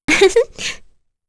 Kirze-Vox-Laugh.wav